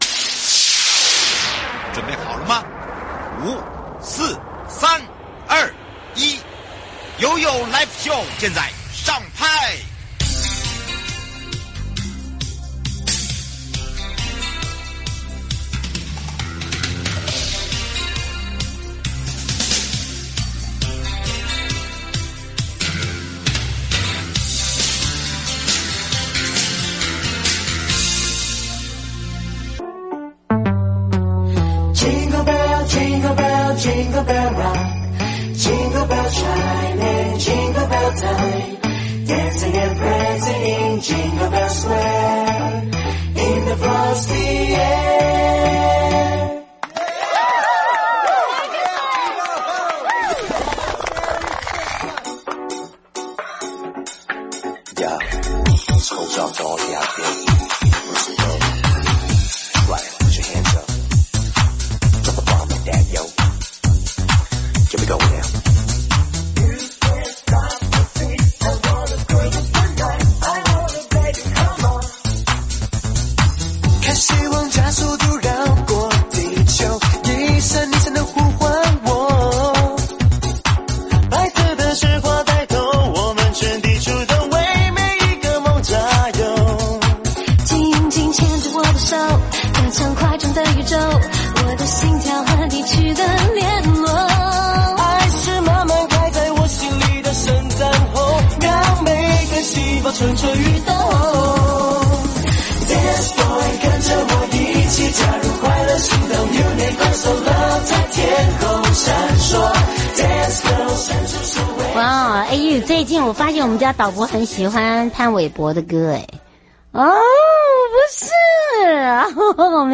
受訪者： 1. 臺灣高等檢察署洪淑姿檢察官 2. 法務部蔡清祥部長 節目內容： 1.